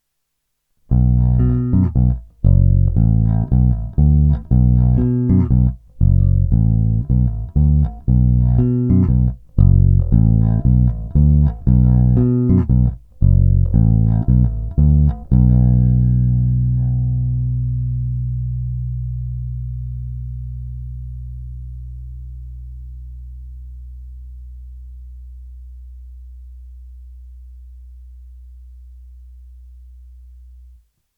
Následující ukázky byly pořízeny s nylonovými hlazenými strunami D'Addario ETB92 Tapewound Bass s tloušťkami .050" až .105."
Hráno vždy nad aktivním snímačem, při zapojení obou snímačů pak mezi nimi.
Oba snímače
Oba snímače se mi líbí nejvíc, zvuk je konkrétnější a má takový dřevitý nádech.